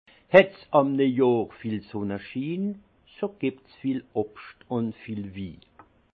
Haut Rhin
Munster